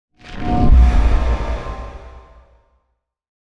divination-magic-sign-rune-intro.ogg